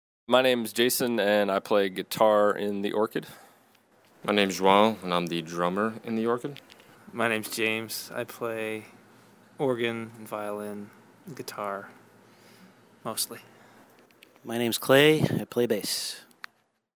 THE ORCHID INTERVIEW – September 2012